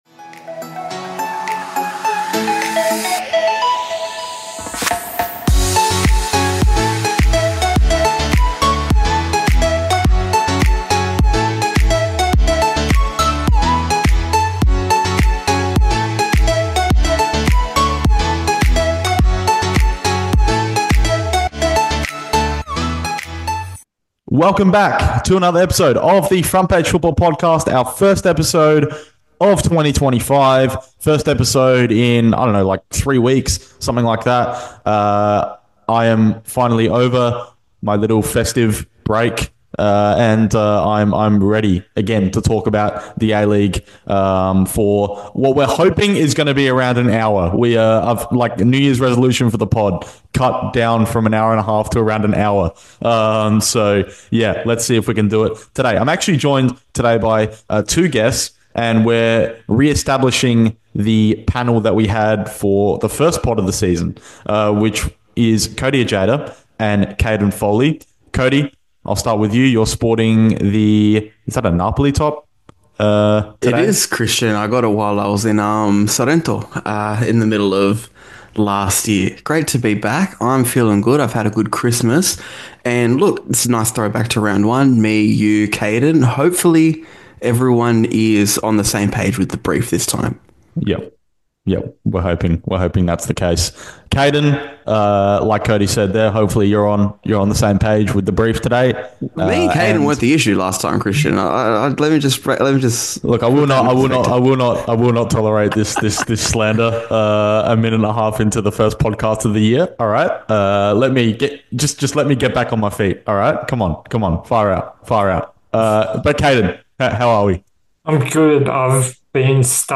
Join the Front Page Football team as they discuss all things Australian football in a casual, ad-libbed, and fun way on this podcast network.